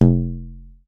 PVC_pipe_hit_3
block bong bonk bottle bumper container drop dropped sound effect free sound royalty free Music